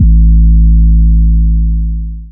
DDW3 808 4.wav